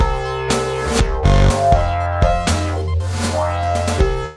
先ほどのリズムにベースとシンセ音を追加したフレーズ・サンプル（MP3）